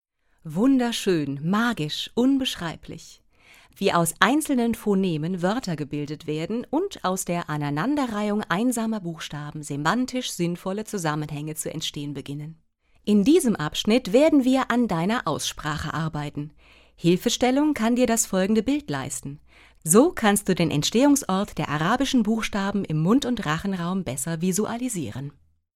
Sprecherin
Als Off-Sprecherin habe ich Kontakte zu professionellen Tonstudios, in denen Ihre Aufträge in bester Qualität bearbeitet werden.
Einfuehrung-in-das-moderne-Arabisch.mp3